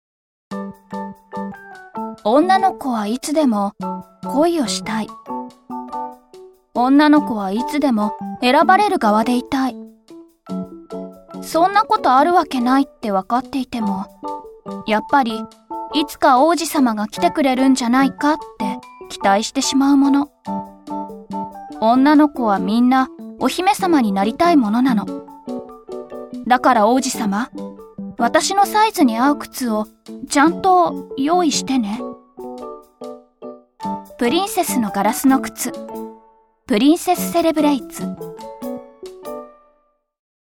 声優もできる確かな演技力を持つナレーター。柔らかでありながら通る声質、少女から大人の女性までこなせる幅広い音域が持ち味。